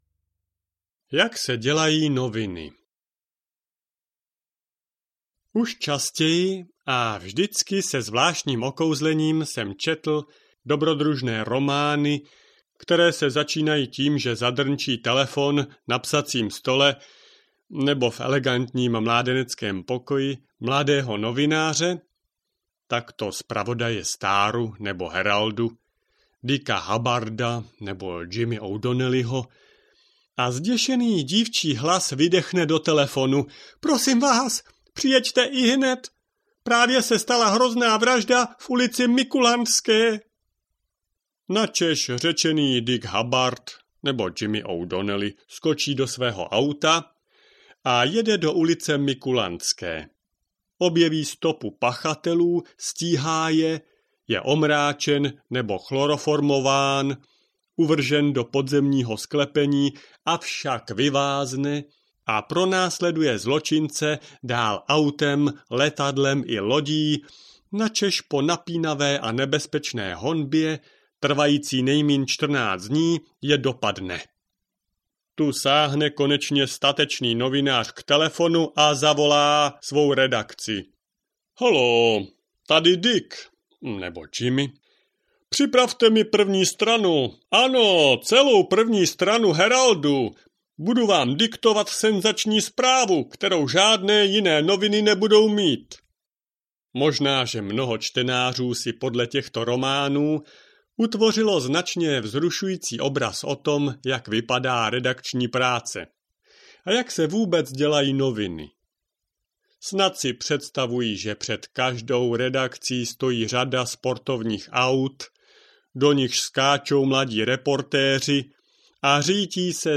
Audio knihaJak se co dělá
Ukázka z knihy